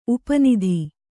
♪ upa nidhi